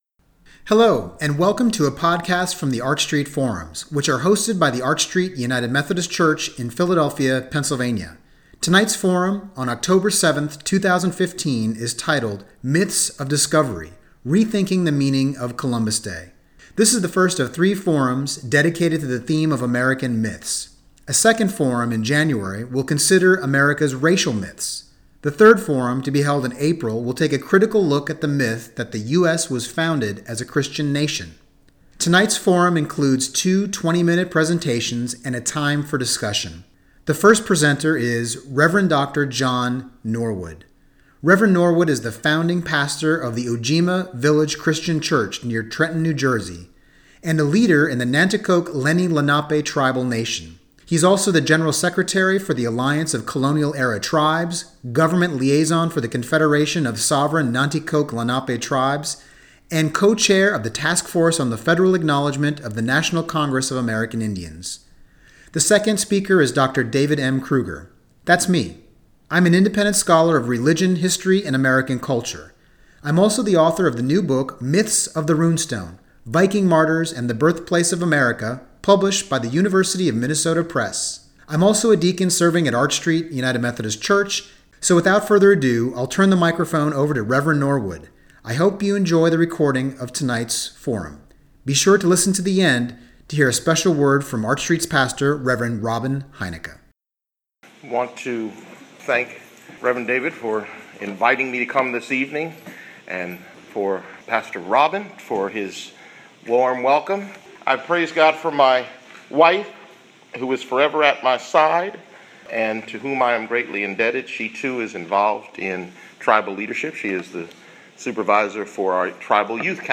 American Myths Symposium #1: Re-Thinking the Meaning of Christopher Columbus — October 7, 2015